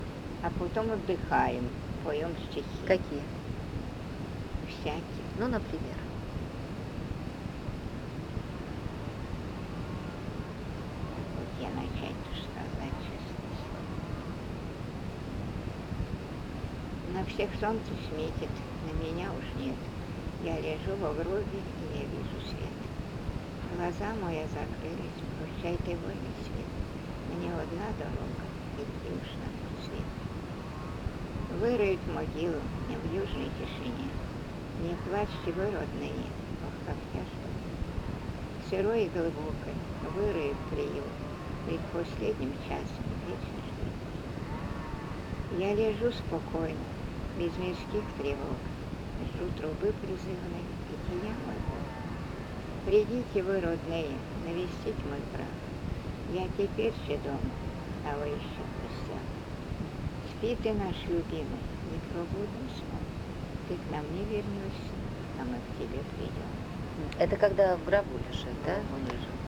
Поминальный стих
Место фиксации: Владимирская область, Муромский район, деревня Михайлово Год